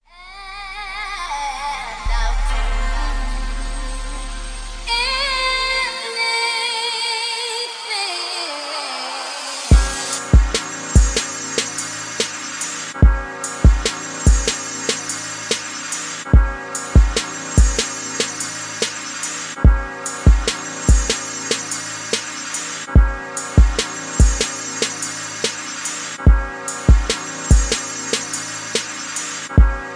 Smooth hiphop